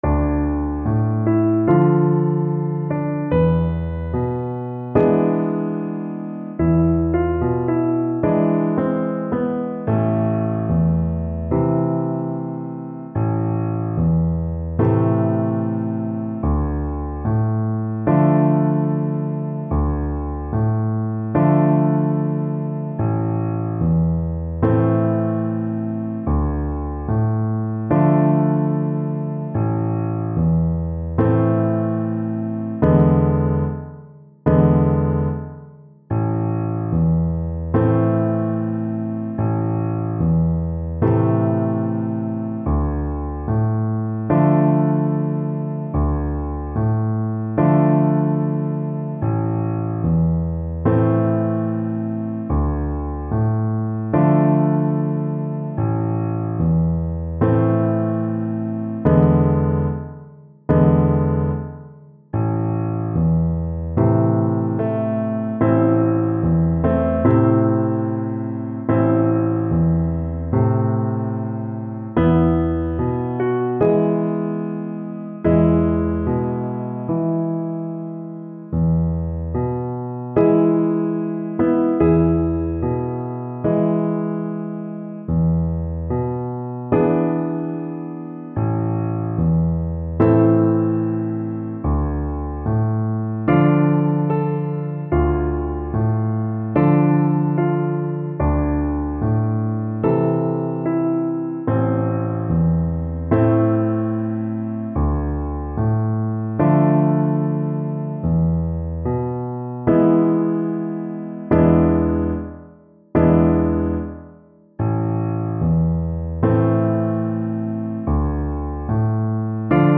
Play (or use space bar on your keyboard) Pause Music Playalong - Piano Accompaniment Playalong Band Accompaniment not yet available transpose reset tempo print settings full screen
Flute
A minor (Sounding Pitch) (View more A minor Music for Flute )
Adagio
4/4 (View more 4/4 Music)
Traditional (View more Traditional Flute Music)
world (View more world Flute Music)